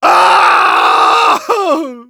Engineer_paincrticialdeath06_de.wav